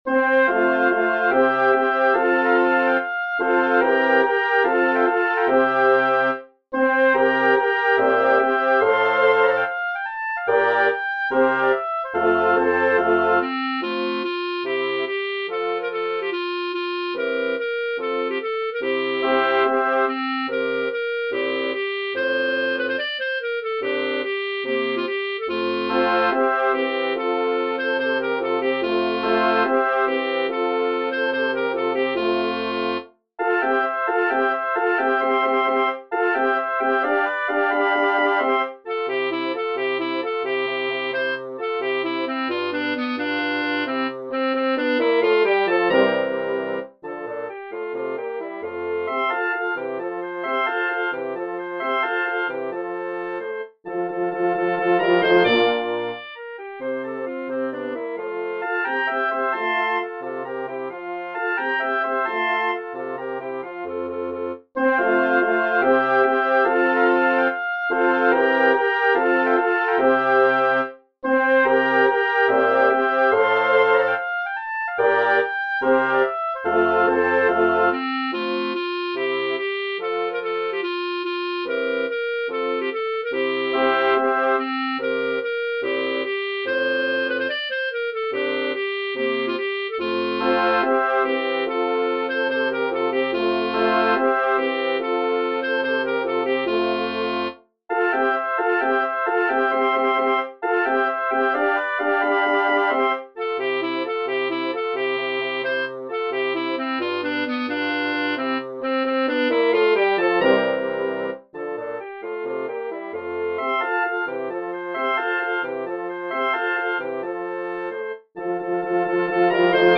Mädchen oder Weibchen W. A. Mozart Bläseroktett